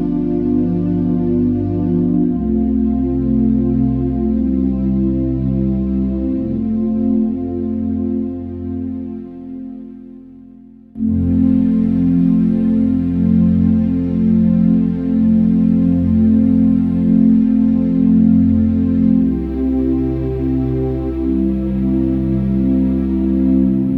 No Backing Vocals Easy Listening 2:57 Buy £1.50